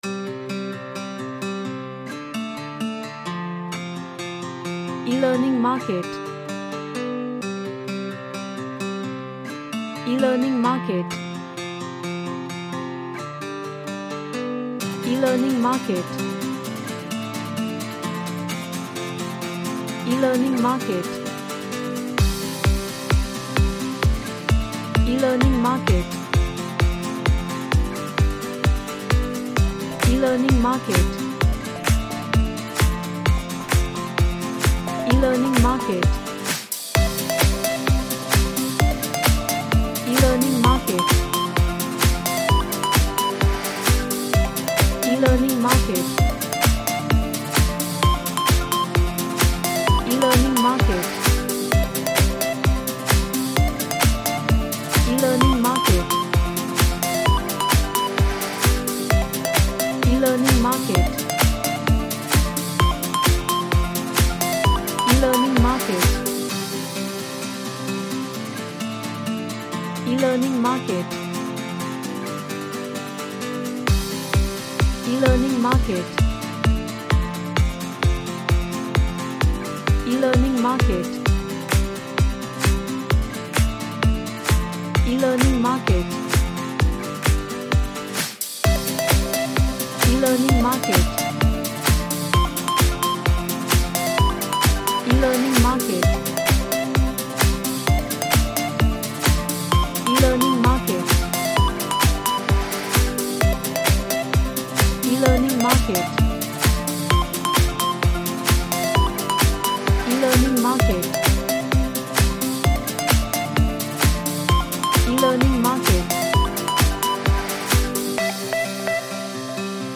Upbeat and cheerful music
Happy / Cheerful